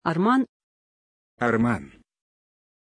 Pronunciation of Arman
pronunciation-arman-ru.mp3